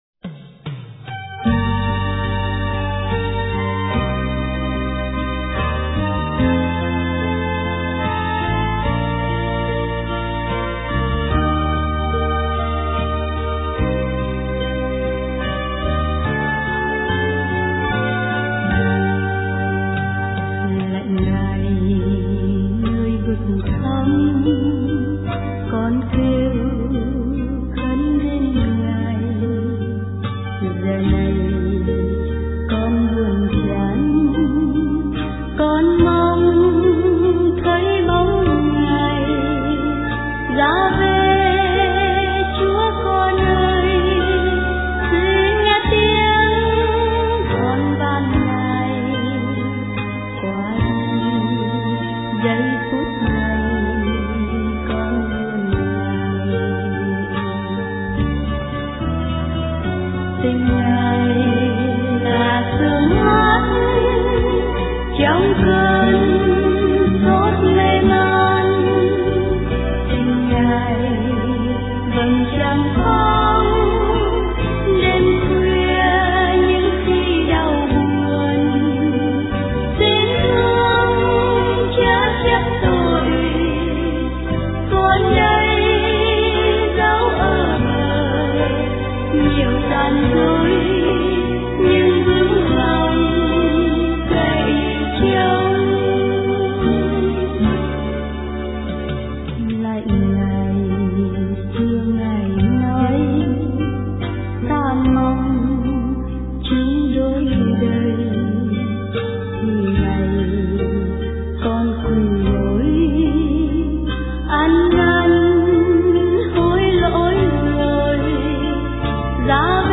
* Thể loại: Cầu hồn